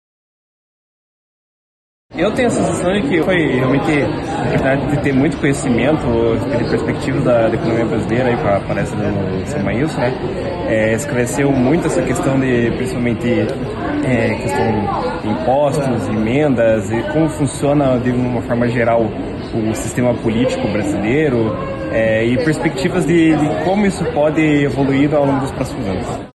O público que acompanhou a palestra destacou a importância de conhecer mais sobre a economia brasileira.